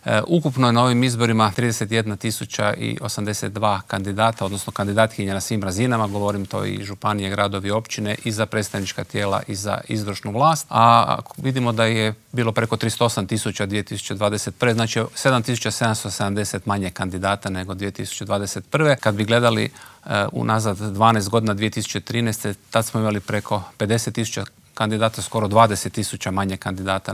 O nadolazećim izborima, pravilima glasovanja, ali i o izbornoj šutnji razgovarali smo u Intervjuu tjedna Media servisa s članom Državnog izbornog povjerenstva Slavenom Hojskim.